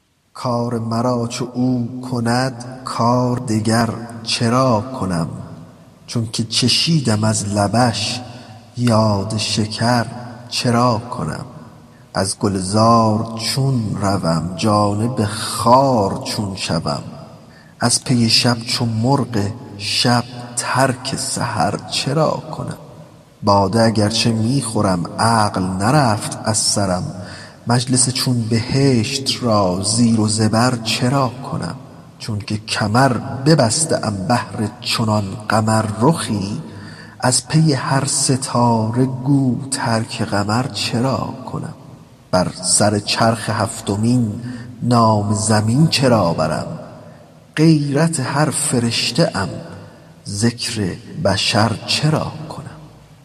غزل شمارهٔ ۱۴۰۴ به خوانش